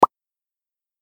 5. Pop Up Message Notification Tone
This is a short and stylish pop notification sound that is perfect for SMS or app alerts.
pop_up_message_notification_tone.mp3